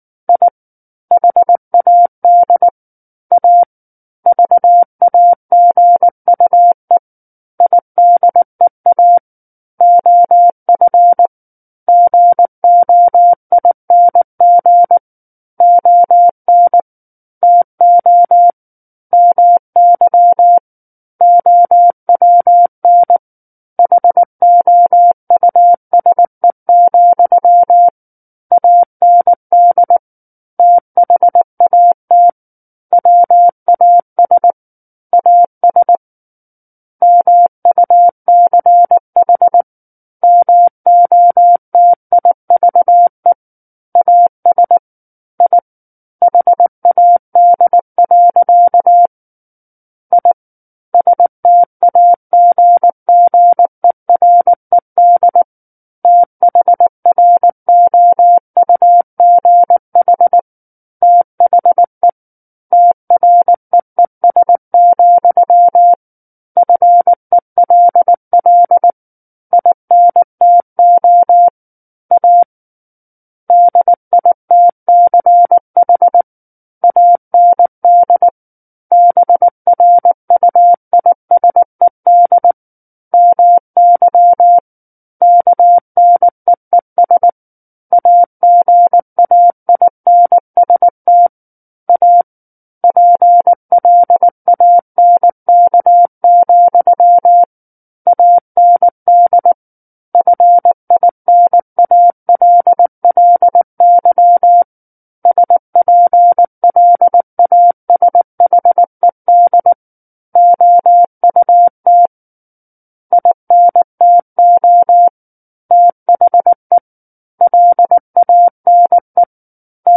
War of the Worlds - 10-Chapter 10 - 19 WPM